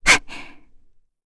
Frey-Vox_Attack1.wav